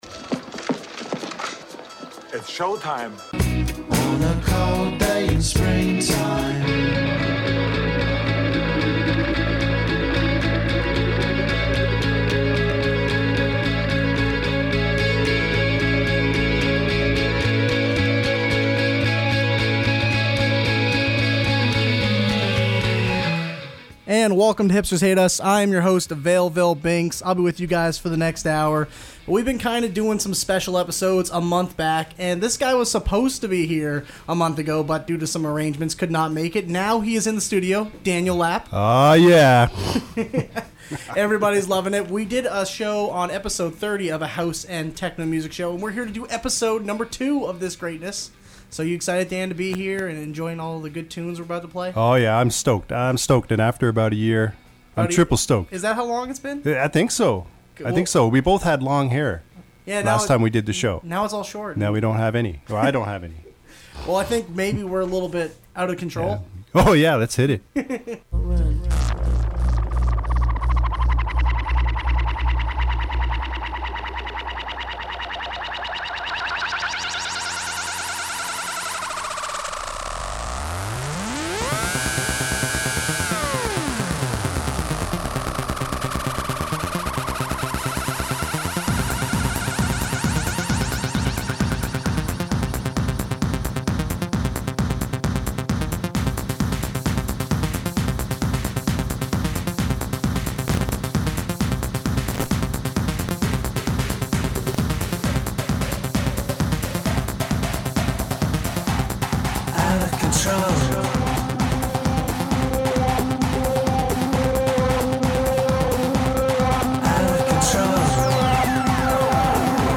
An Open Format Music Show. Britpop, Electronica, Hip-Hop, Alternative Rock, and Canadian music